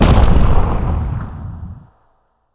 client / bin / pack / sound2 / sound / monster2 / fire_dragon / attack1_3.wav
attack1_3.wav